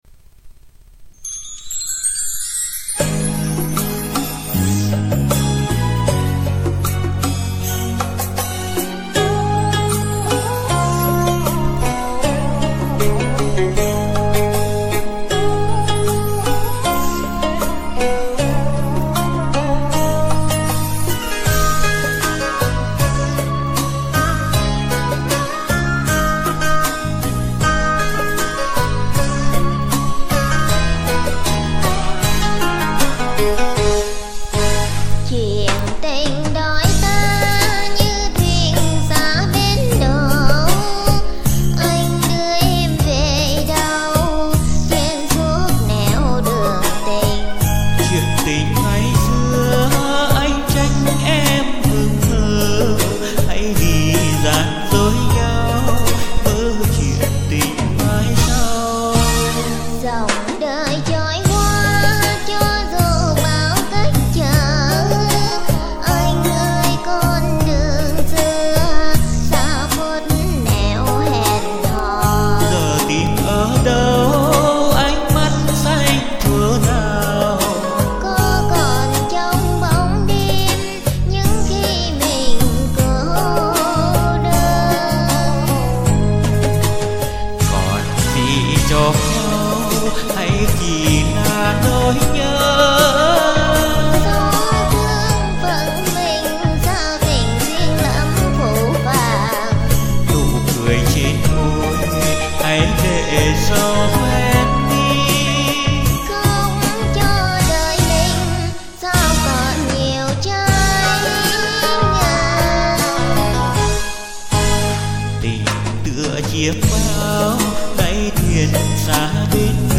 Tôi và bạn tôi đã song ca ca khúc